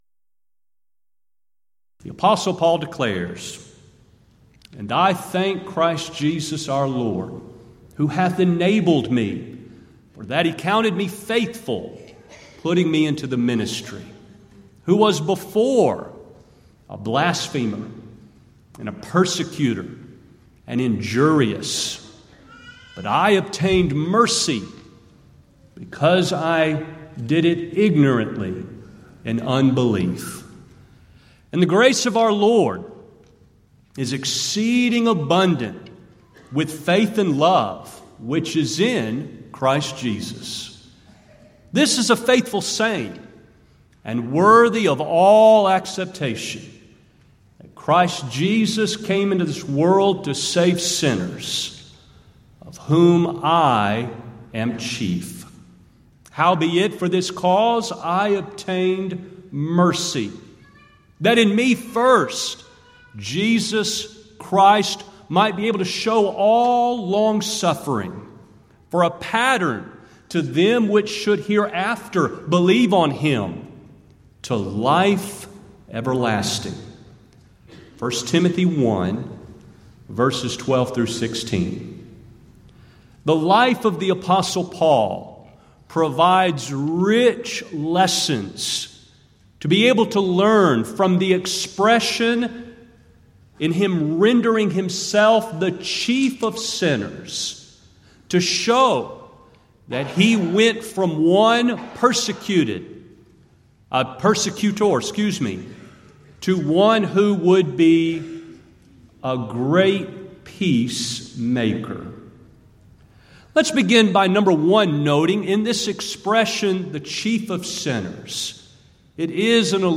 Event: 33rd Annual Southwest Lectures
If you would like to order audio or video copies of this lecture, please contact our office and reference asset: 2014Southwest04